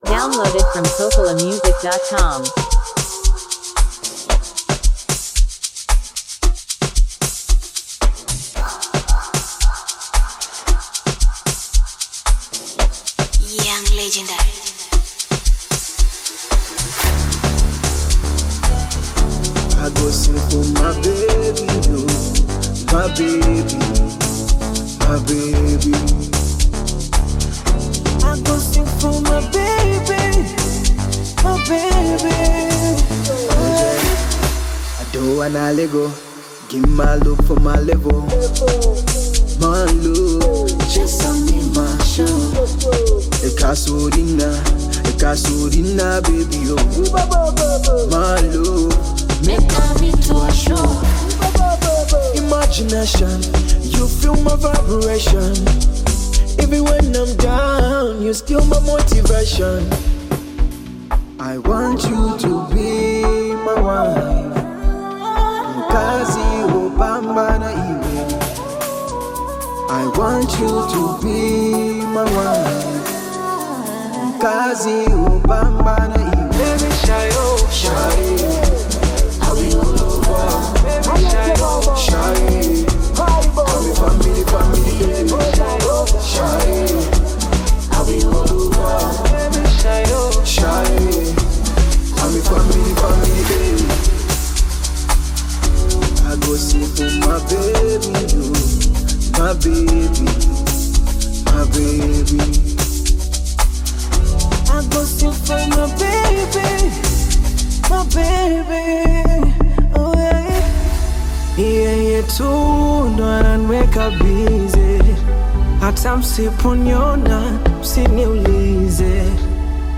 expressive vocals